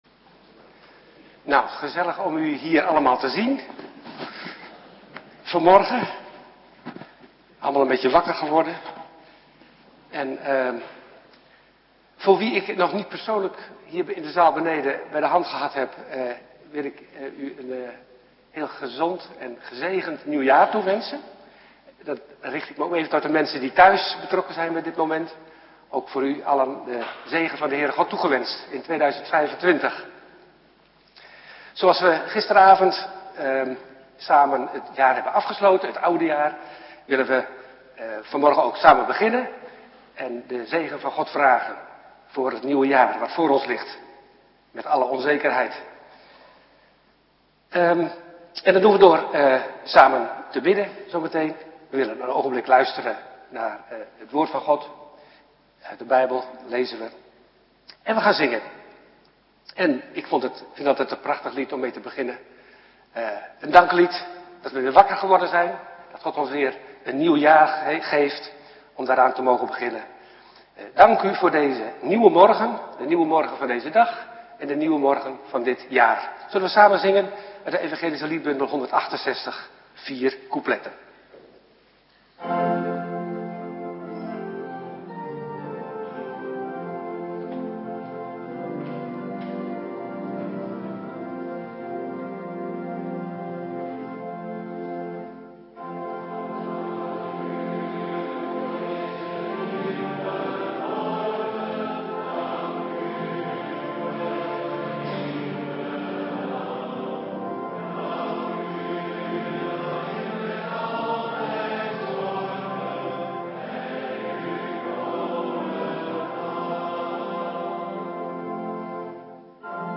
Morgendienst 2024-04-28 09:30